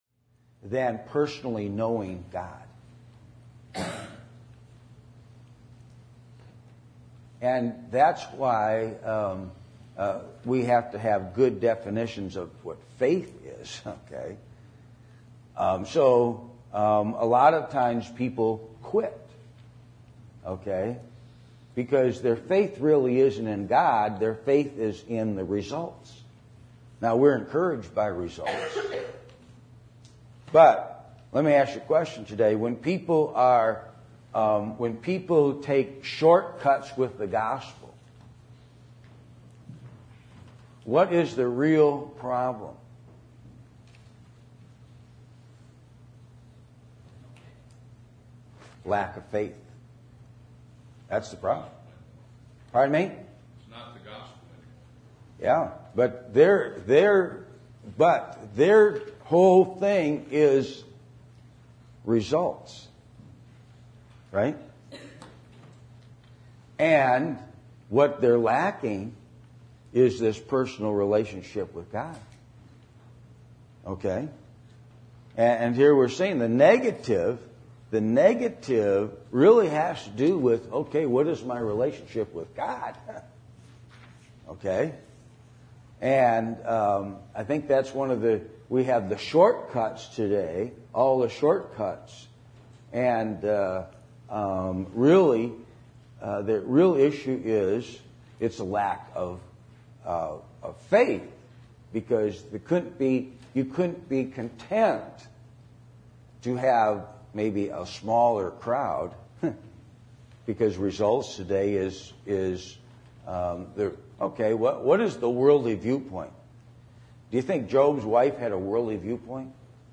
Negative Faith (truncated recording, technical difficulty)
Service Type: Adult Sunday School